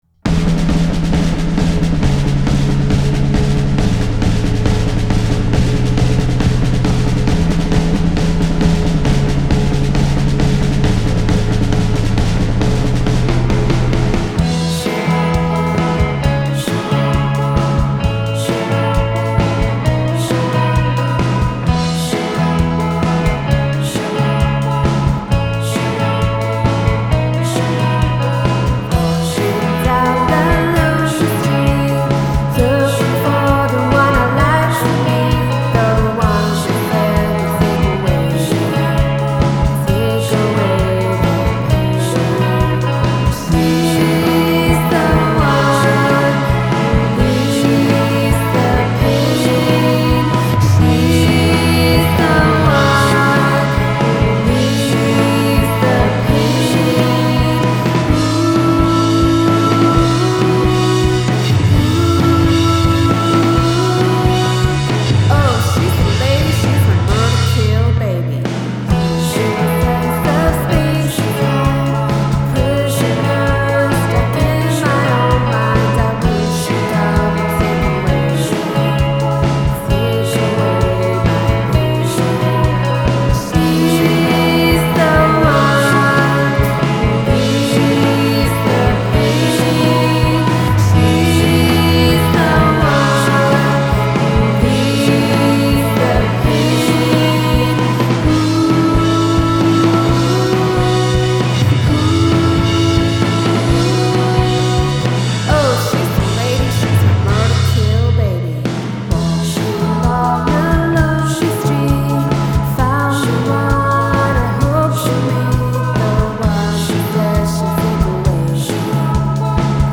la band di Singapore